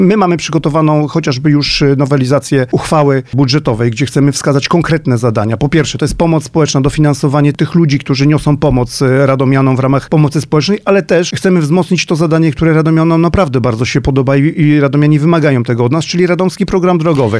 W ten sposób miasto chce pozyskać pieniądze na konkretne zadania, a podwyżka wygenerowała by zysk w wysokości ponad 11 mln złotych. O tym, w jaki sposób władze miasta chcą rozdysponować kwotę mówi prezydent Radosław Witkowski w Mocnej Rozmowie: